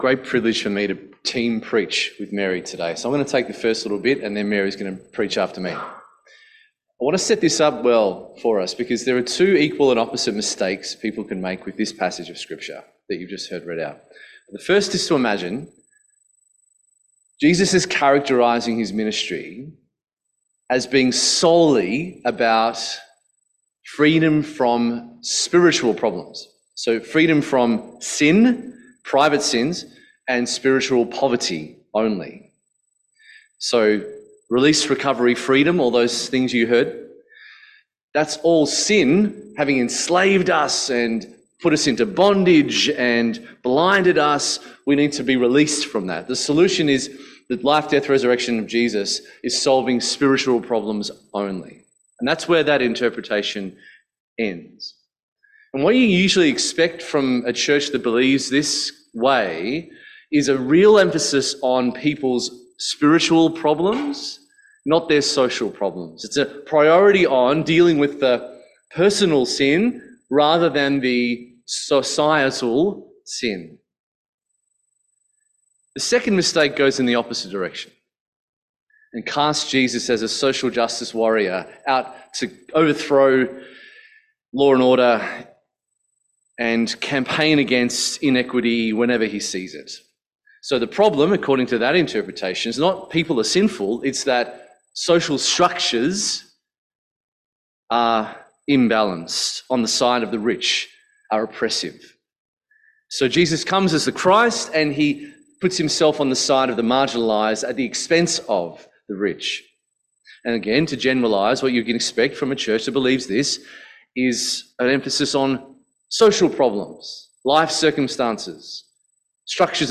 Passage: Luke 4:14-21 Service Type: 5:30pm Resonate Service